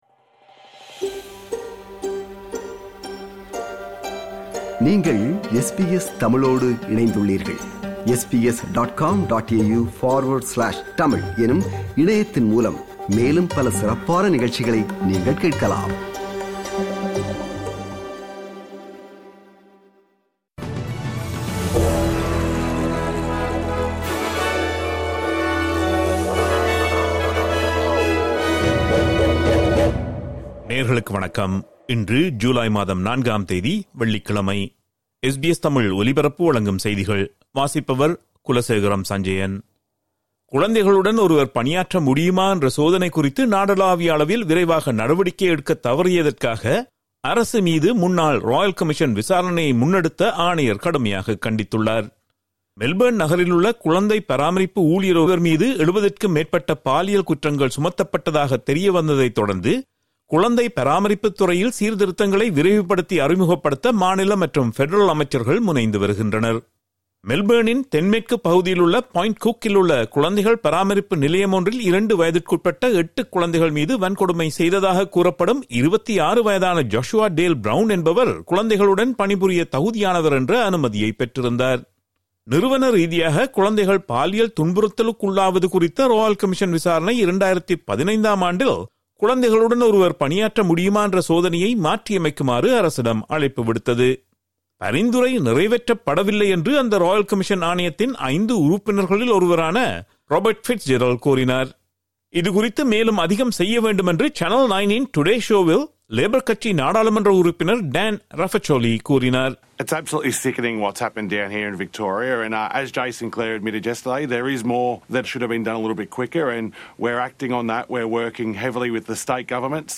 SBS தமிழ் ஒலிபரப்பின் இன்றைய (வெள்ளிக்கிழமை 04/07/2025) செய்திகள்.